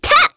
Pansirone-Vox_Attack1_kr.wav